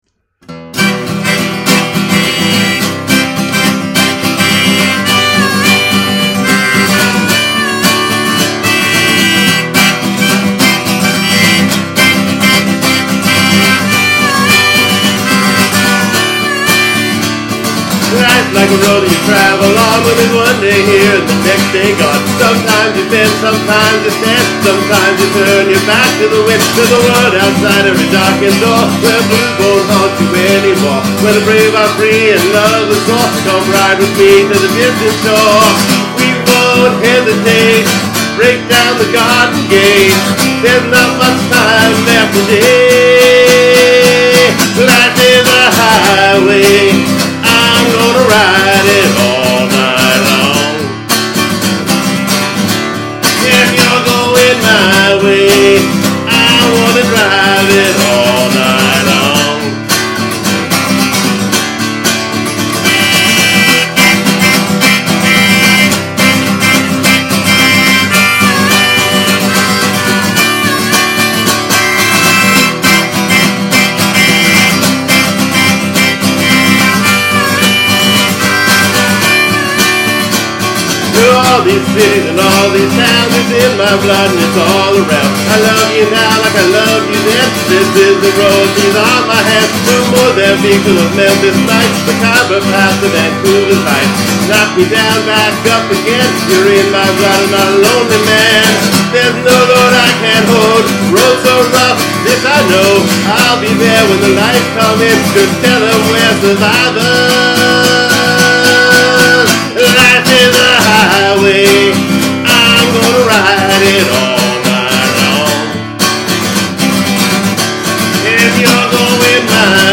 I enjoy playing rock, blues, and folk music.
I recorded covers of the Blue Rodeo song "5 Days in May"